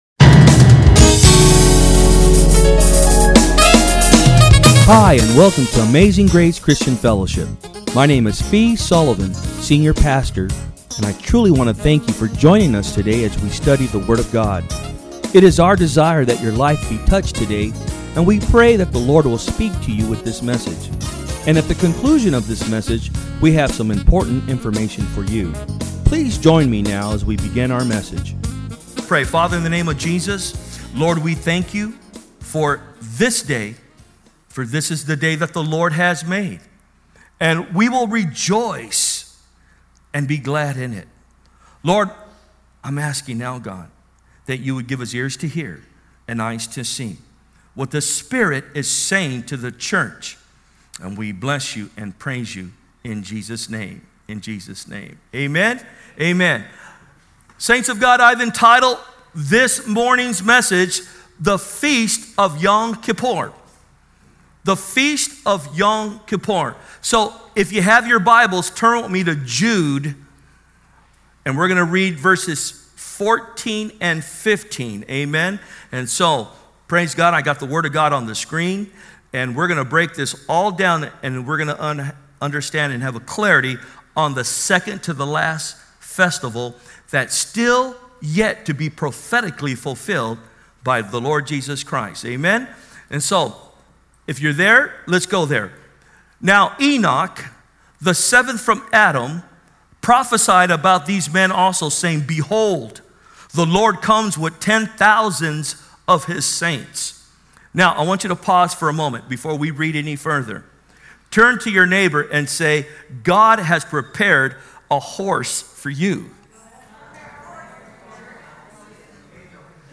From Service: "Sunday Am"